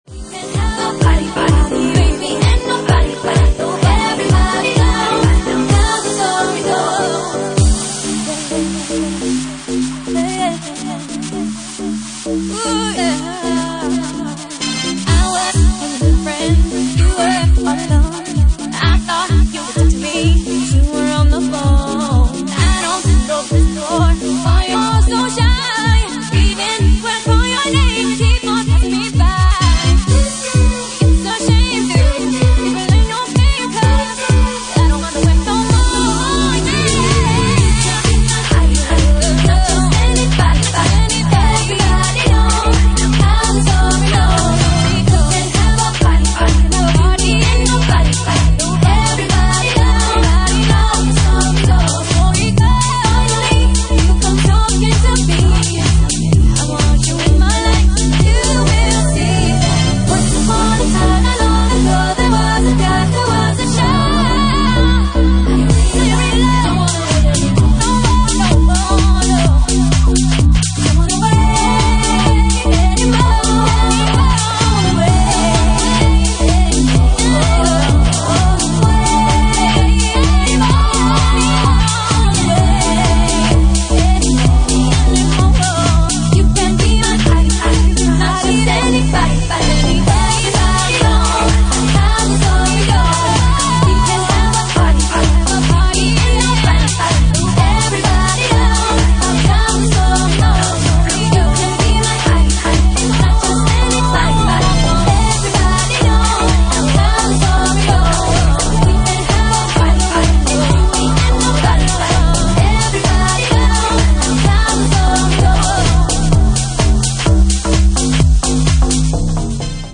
Genre:Jacking House